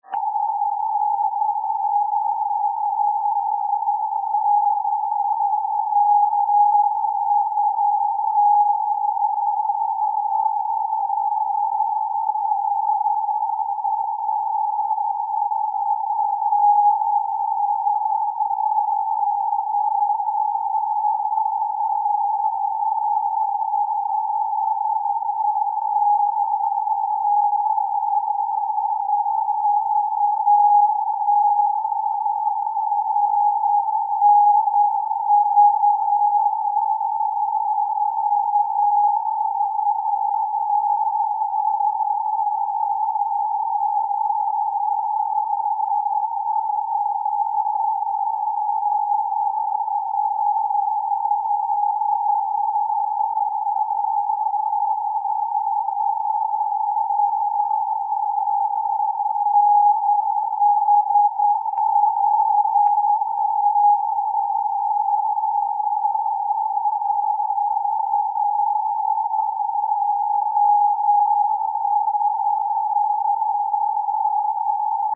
By the night of Aug 12-13 9t had been 11 months since I heard a peep or had seen any reports on NHVT, but a couple of hours after dark, there it suddenly was as a slowly strengthening trace on Argo, along with lots of QRM carriers.
The keying was audible, but badly broken up by rapid, deep fades that often obscured individual elements within letters. The attached MP3 clip is typical of that evening...lots of dits and dahs at seemingly random times, one instance with parts of two identifier cycles ("VT NH"), and then finally at 59 seconds into the clip, one complete ID.